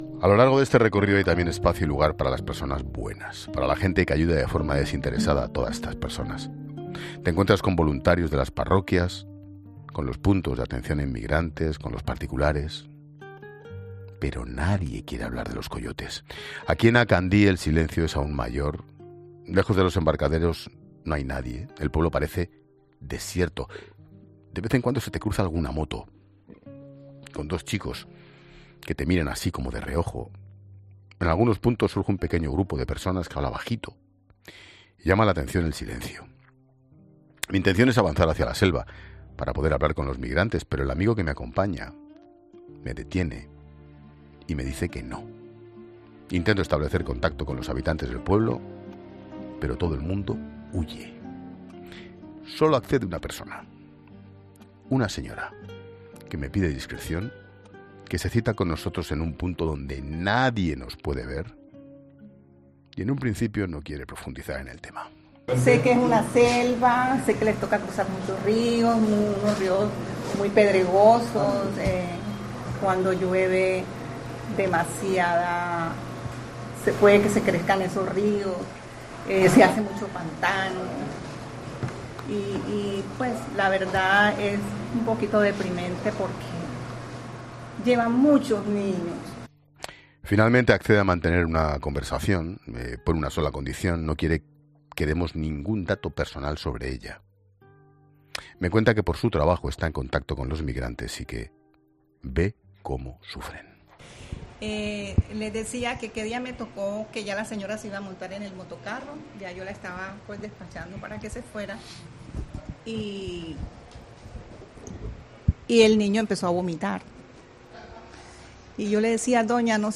El director de La Linterna se ha desplazado hasta Acandí y ha quedado en un punto exacto, donde nadie les puede ver, con una señora que le ha explicado la situación de los niños
Una señora que se encuentra en Acandí le cuenta a Ángel Expósito la situación del Darién